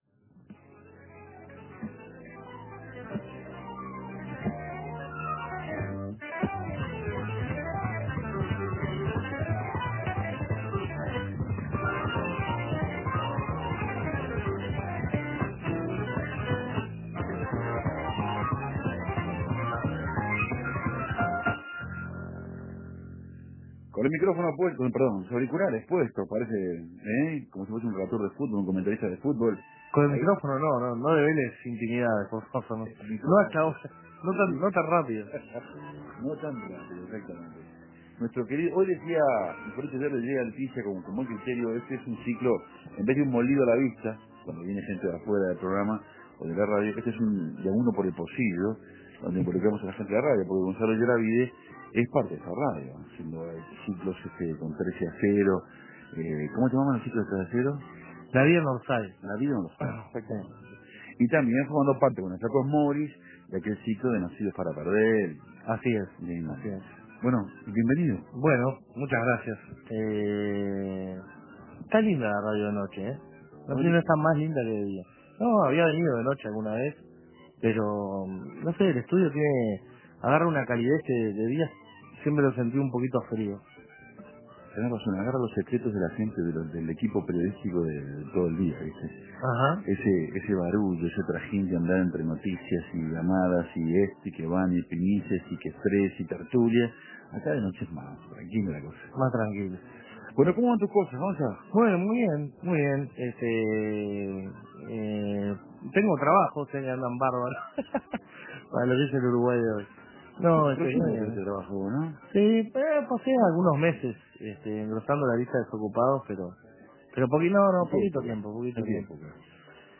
El domingo 22 se lo pudo volver a escuchar en El Espectador, pero esta vez de visita en Café Torrado. Sus últimos trabajos, su infancia, la publicidad y la vuelta a sus orígenes creativos: la historieta.